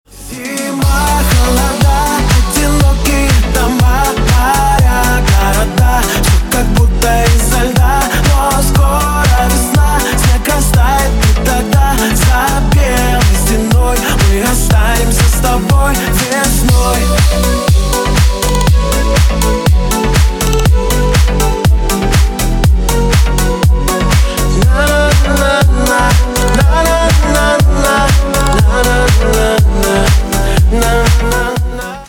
Cover Remix
Dance рингтоны